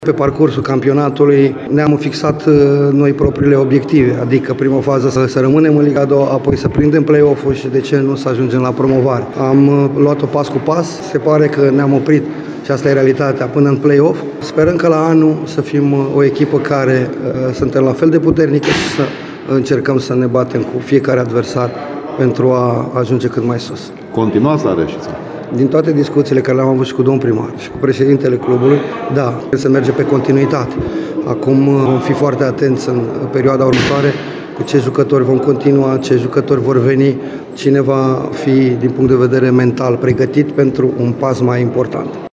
Antrenorul Flavius Stoican a declarat imediat după victoria de ieri, 2-0 în Ghencea, cu CSA Steaua, că e deja cu gândul la sezonul viitor.